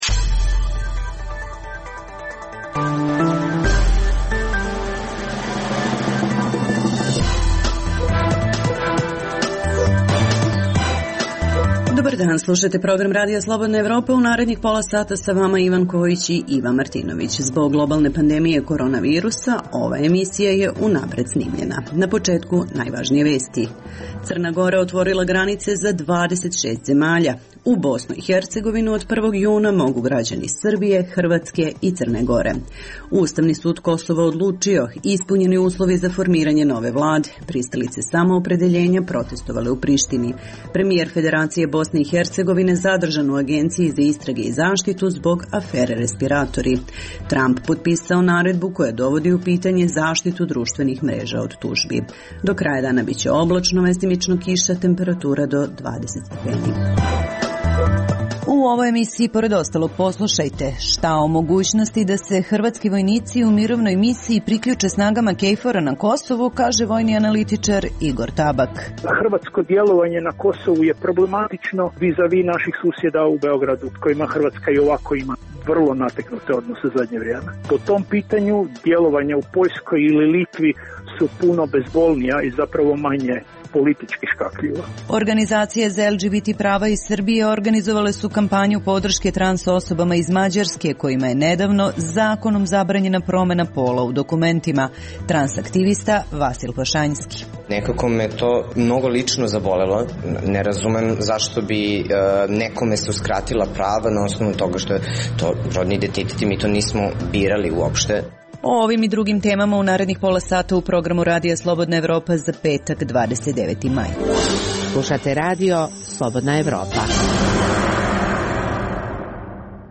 Zbog globalne pandemije korona virusa, ova je emisija unapred snimljena.